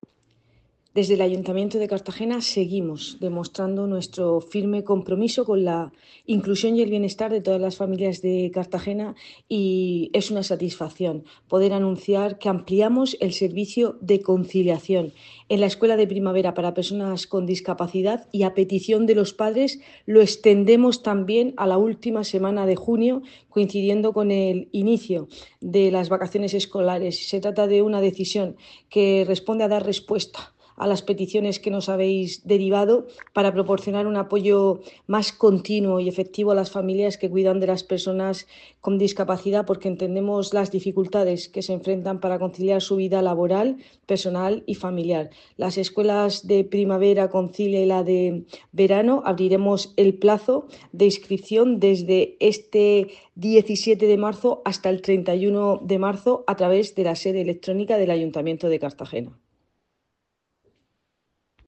Declaraciones de la edil de Política Social, Cristina Mora.